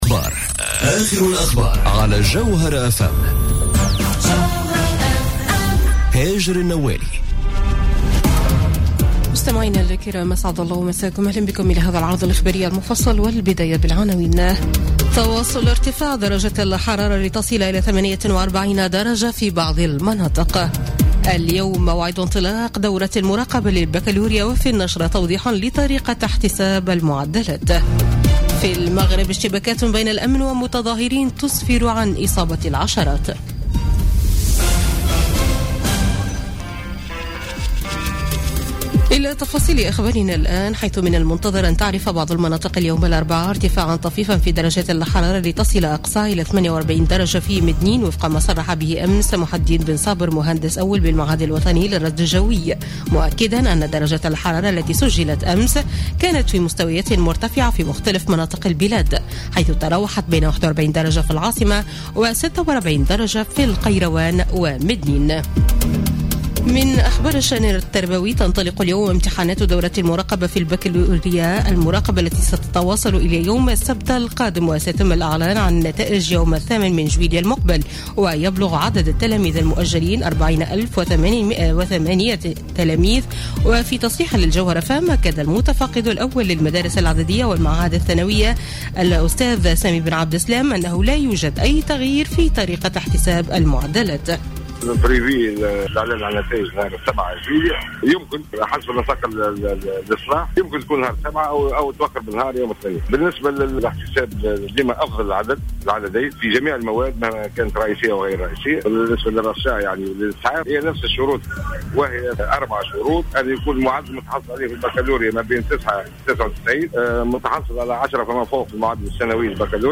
نشرة أخبار منتصف الليل ليوم الأربعاء 28 جوان 2017